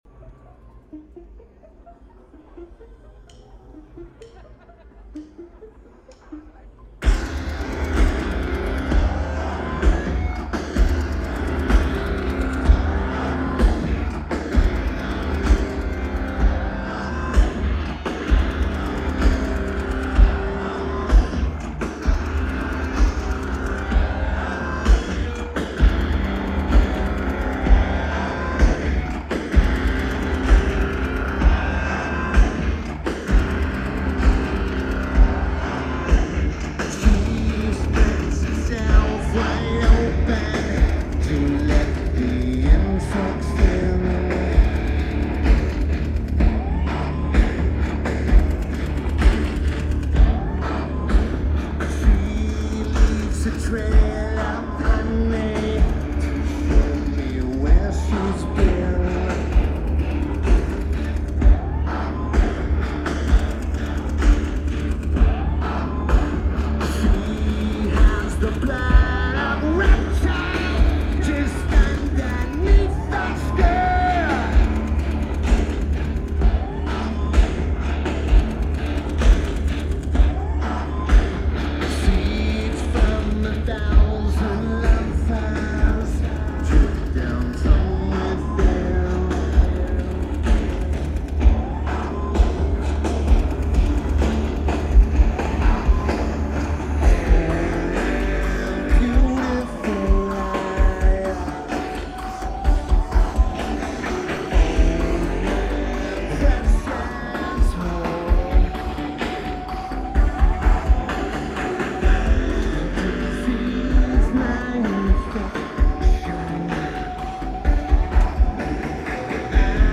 Primavera Sound LA
Lineage: Audio - AUD (AT853 (4.7k mod) + Sony PCM-A10)
All was fixed in post.